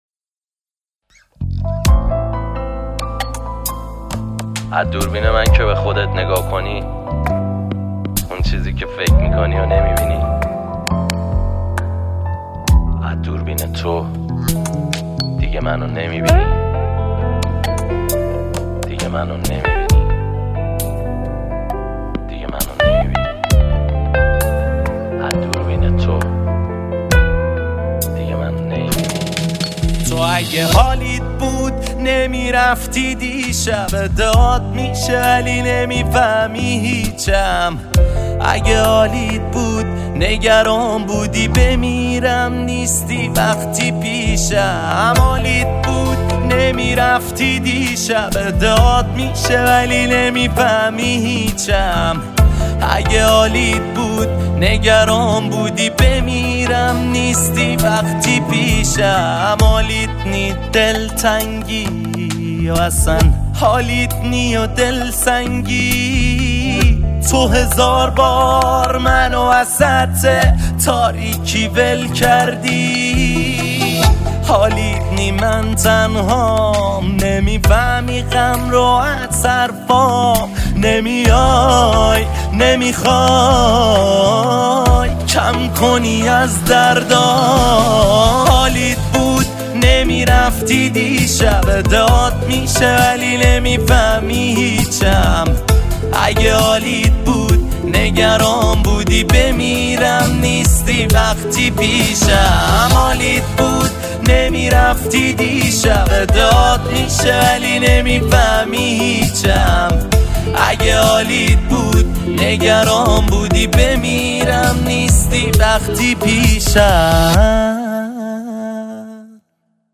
گیتار
گیتار الکتریک
استرینگ